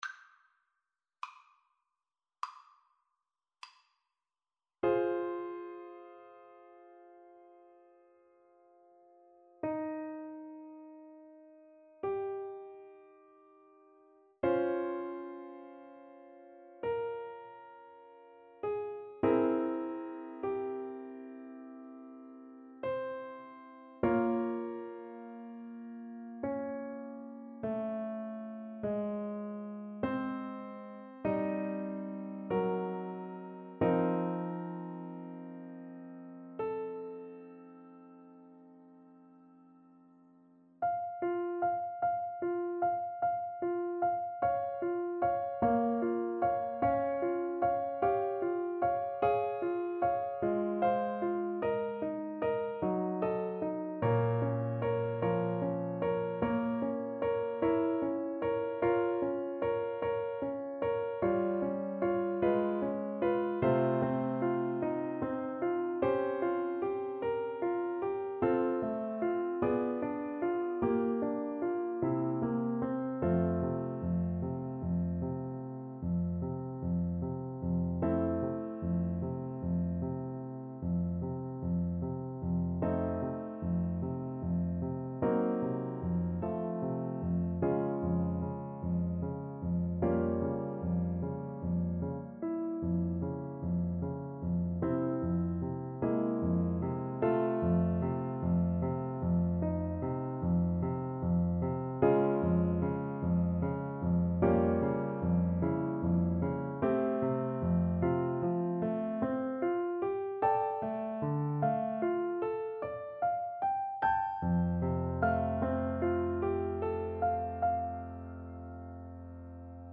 4/4 (View more 4/4 Music)
~ = 100 Lento =50
Classical (View more Classical Clarinet Music)